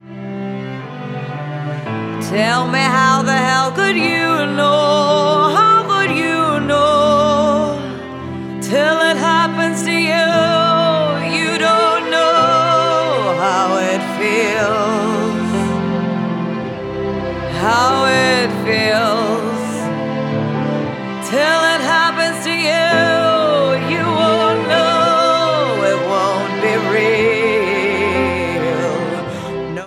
Ringtone
• Pop